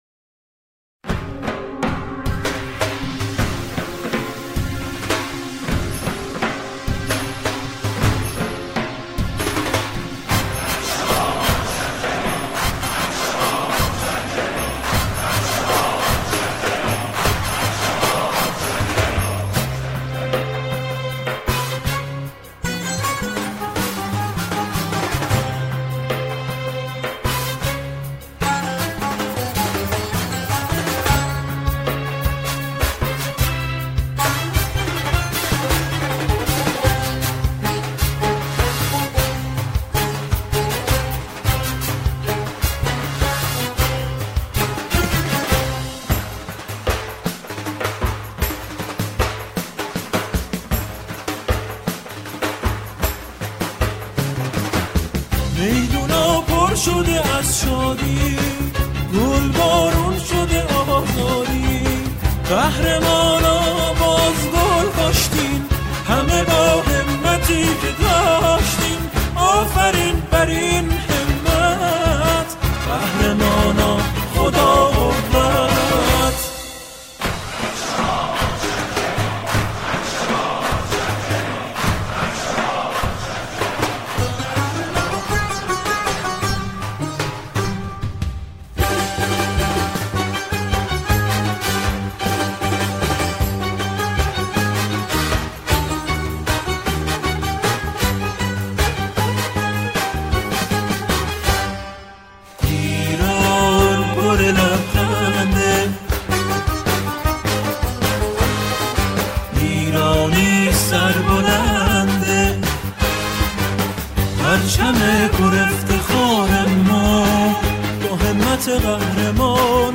سرودهای ورزشی
همخوانی شعری ورزشی